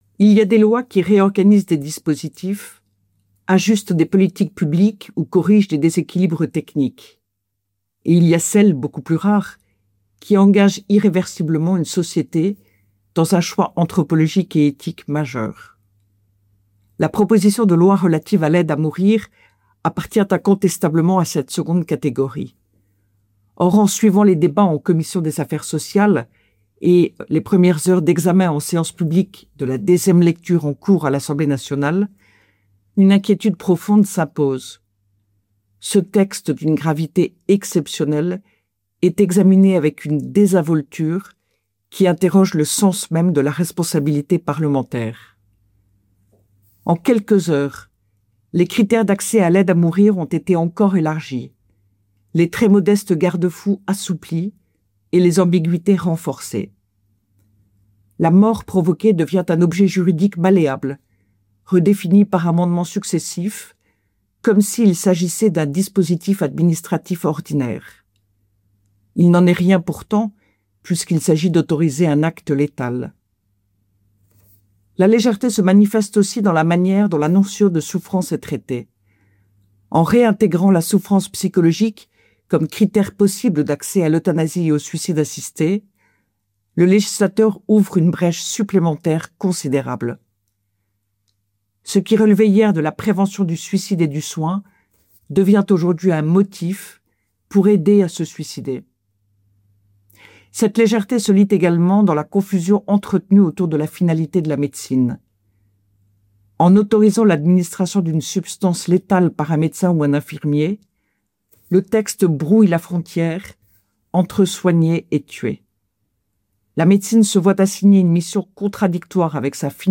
radio-esperance-fin-vie-desinvolture-tourne-mepris.mp3